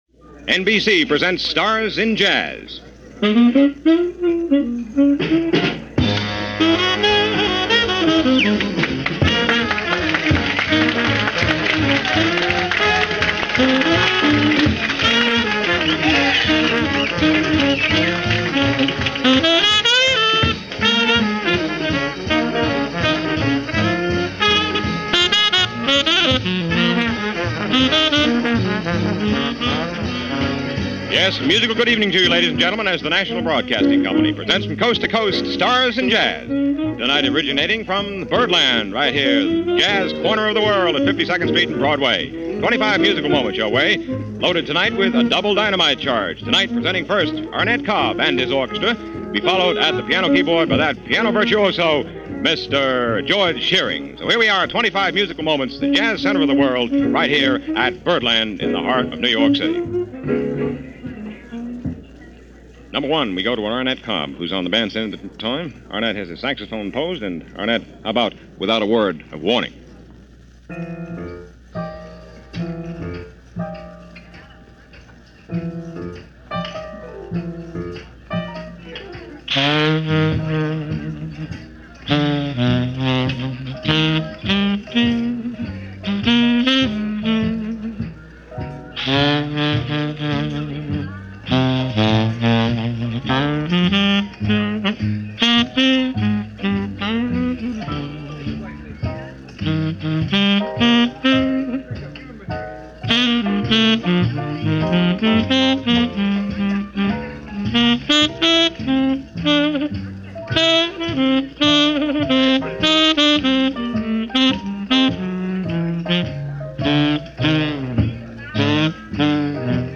recorded live from Birdland on July 3rd 1952.